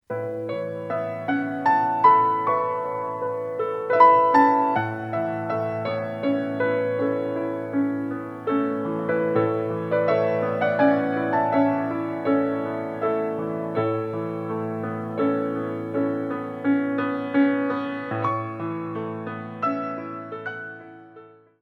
These are original pieces in a classical style.